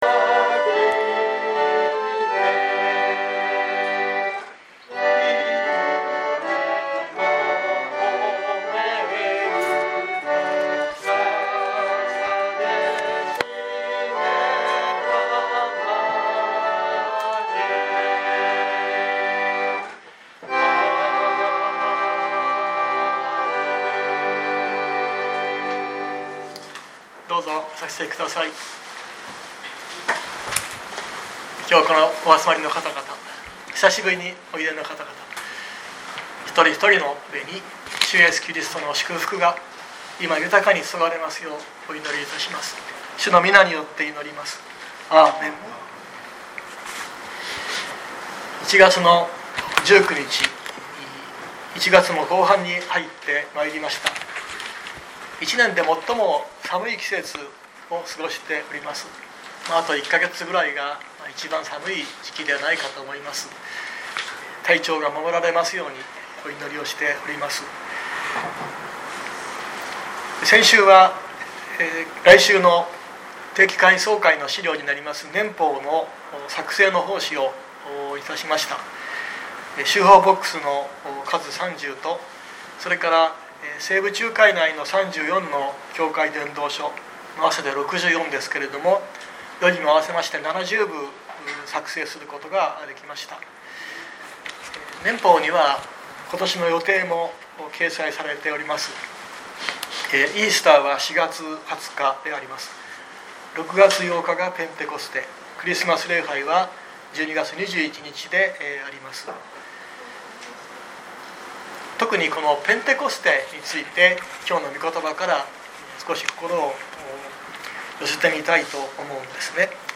2025年01月19日朝の礼拝「魔術師シモンの回心２」熊本教会
熊本教会。説教アーカイブ。